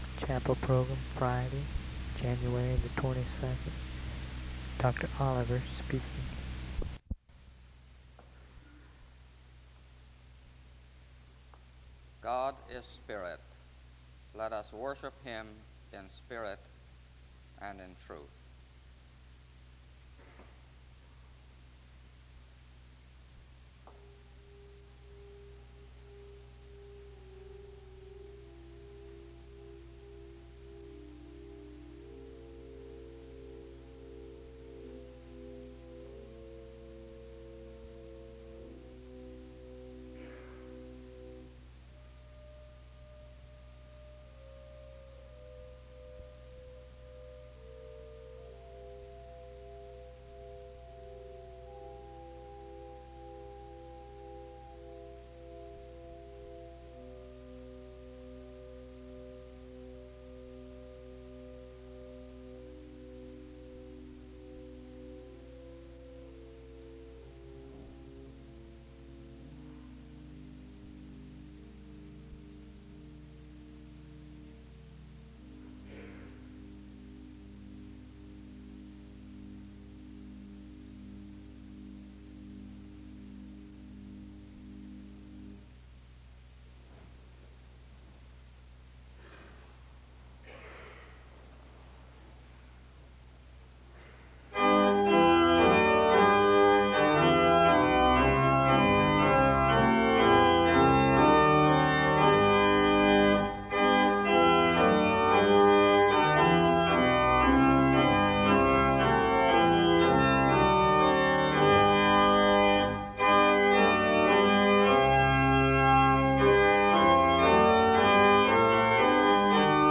The service begins with music from 0:00-4:55. A responsive reading is read from 5:15-6:37. A prayer is offered from 6:43-7:19.